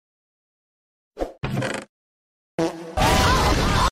Bocil Buka Kotak & Meledak Sound Effects Free Download